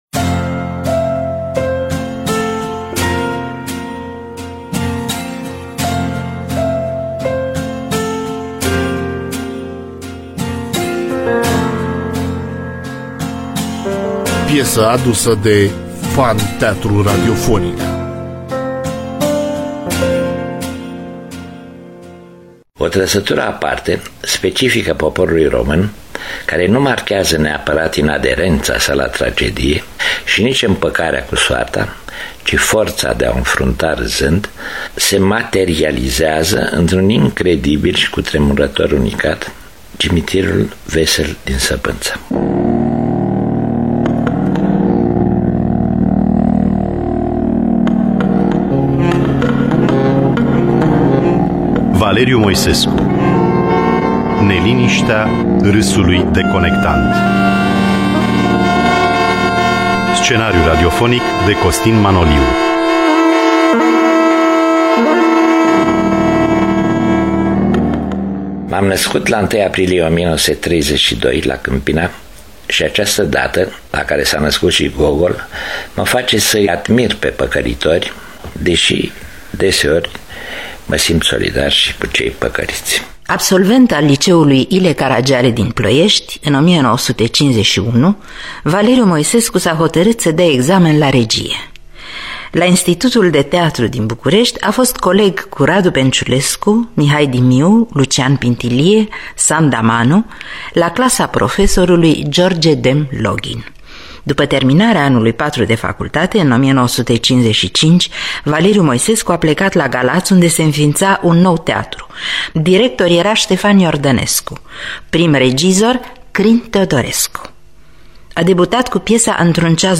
Cu participarea extraordinară a regizorului Valeriu Moisescu.